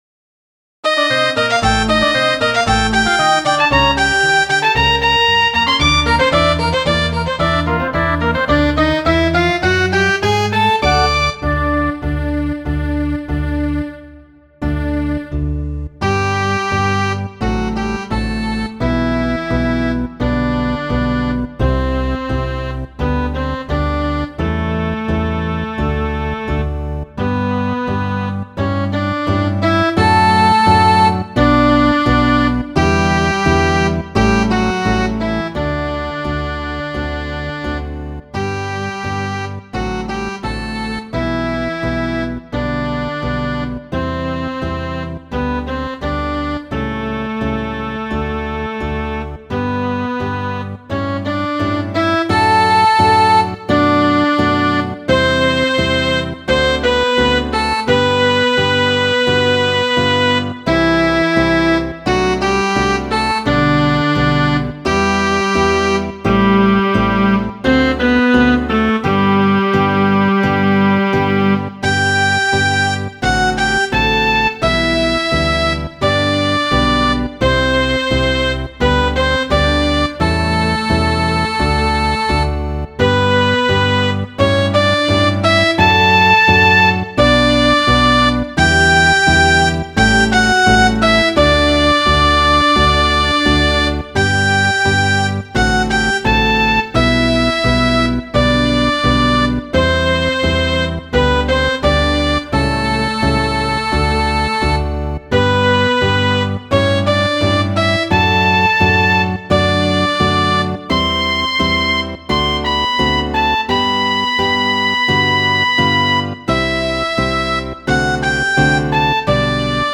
enkomputiligita